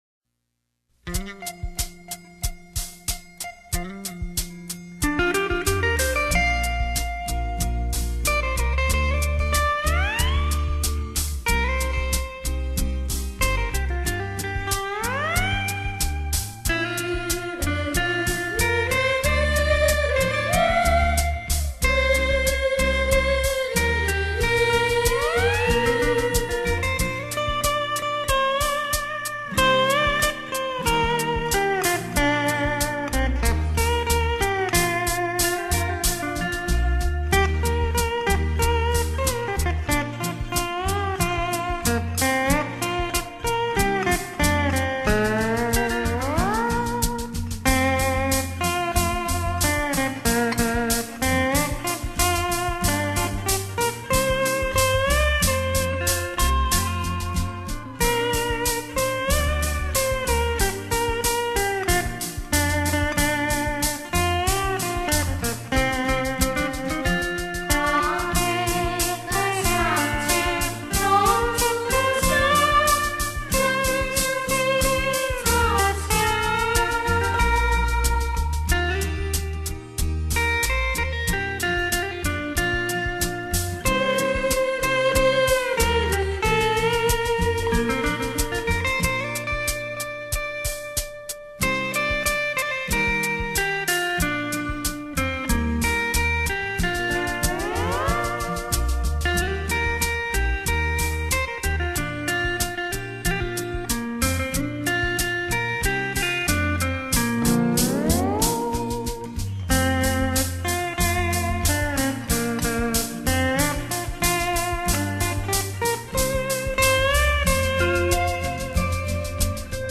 优美的琴声，令人心旷神怡的遐想......